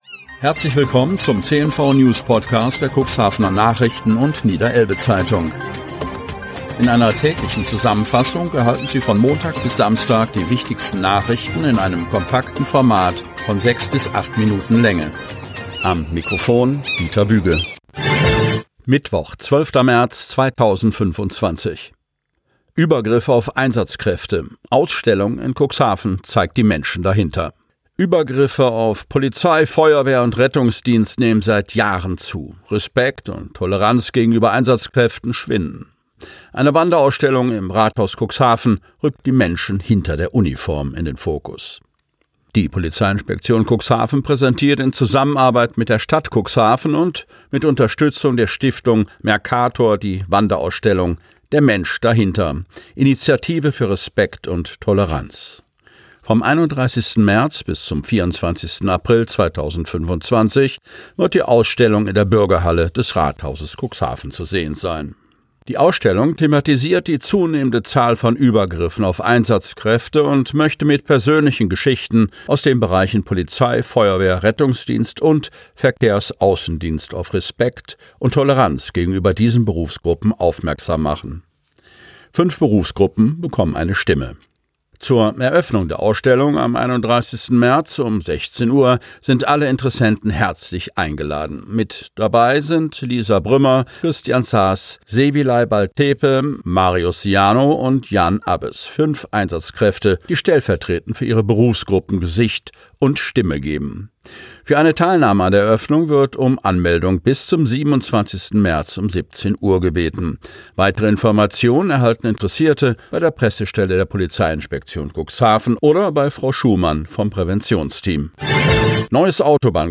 Ausgewählte News der Cuxhavener Nachrichten und Niederelbe-Zeitung am Vorabend zum Hören!